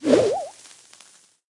Media:Dynamike_base_atk_2.wav 攻击音效 atk 经典及以上形态攻击音效